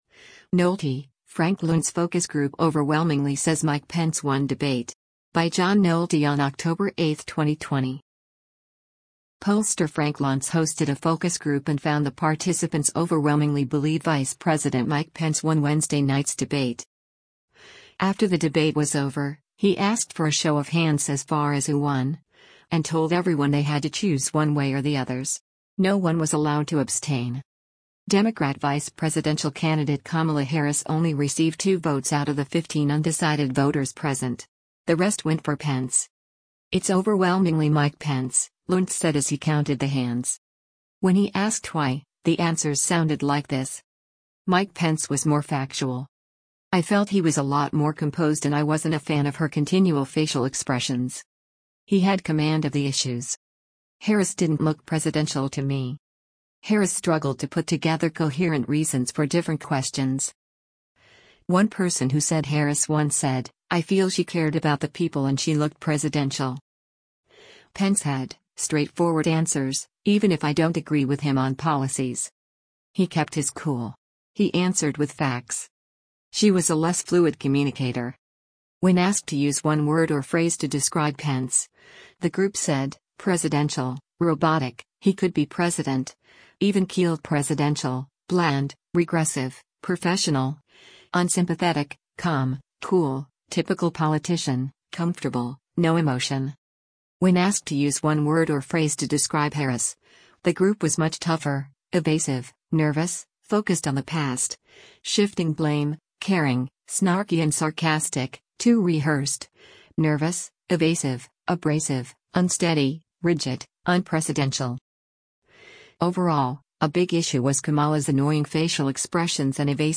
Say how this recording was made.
After the debate was over, he asked for a show of hands as far as who won, and told everyone they had to choose one way or the others.